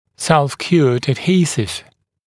[ˌself’kjuəd əd’hiːsɪv][ˌсэлф’кйуэд эд’хи:сив]самоотверждаемый адгезив